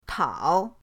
tao3.mp3